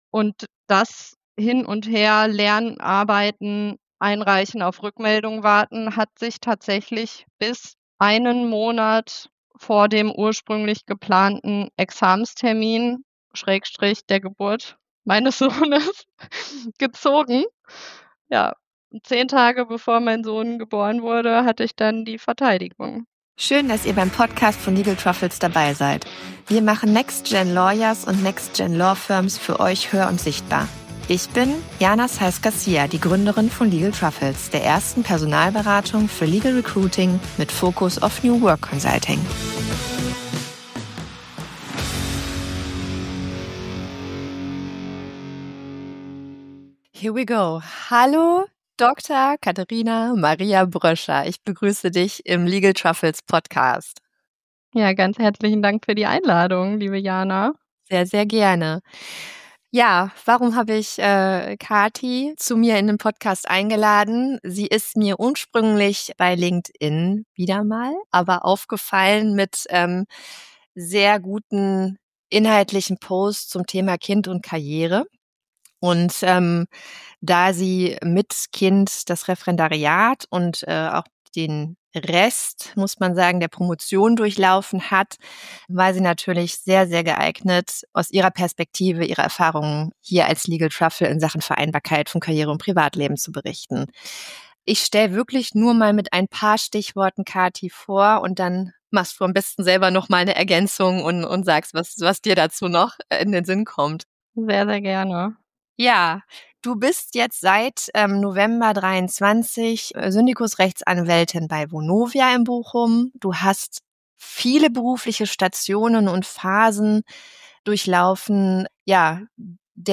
Wie sie das alles gemeistert hat und was ihre Antreiber sind, erfahrt ihr in diesem Interview, in dem sie auch mir einige Fragen zugespielt hat, da ich selbst zum Thema Arbeiten und Promovieren mit Kind einiges berichten kann.